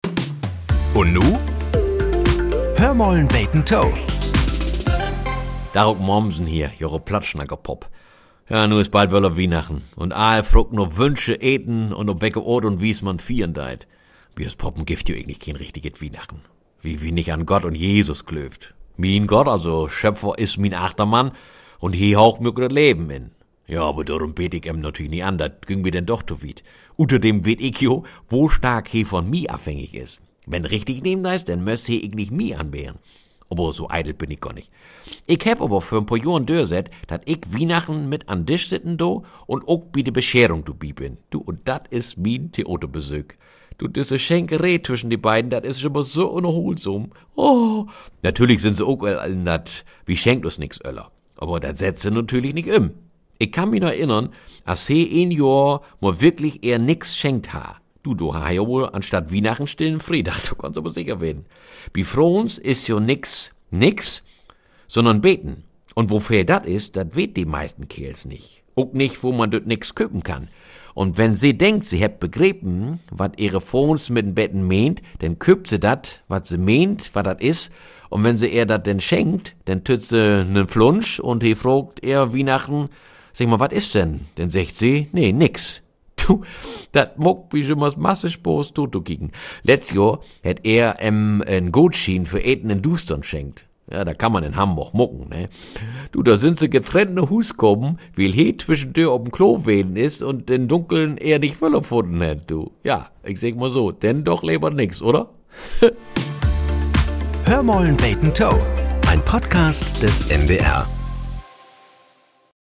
Nachrichten - 14.02.2025